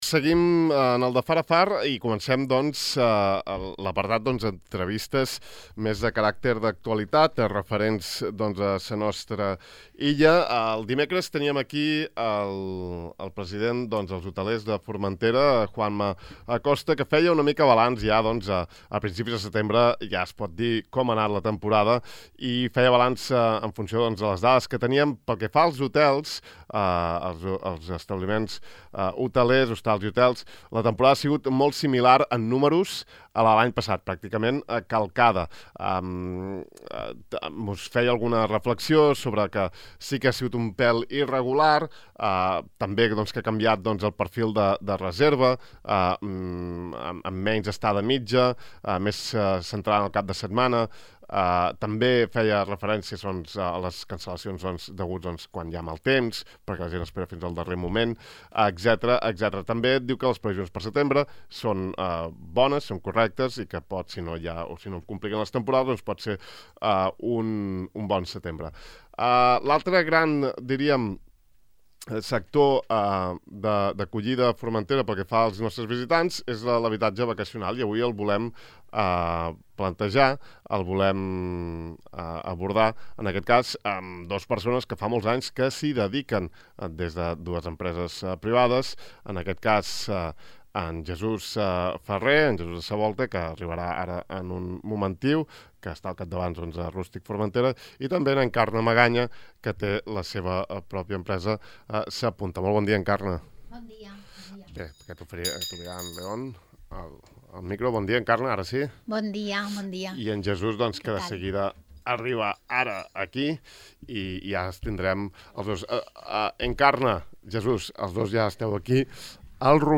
Aquesta setmana hem entrevistat